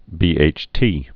(bēāch-tē)